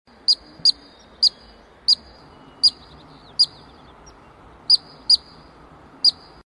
🔊 Columbian Ground Squirrel Sounds sound effects free download
This ground squirrel's chirping sound is used both for alarm calls and mating calls. The context and timing is required to determine which of the two meanings it has.